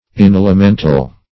Search Result for " inalimental" : The Collaborative International Dictionary of English v.0.48: Inalimental \In*al`i*men"tal\, a. Affording no aliment or nourishment.
inalimental.mp3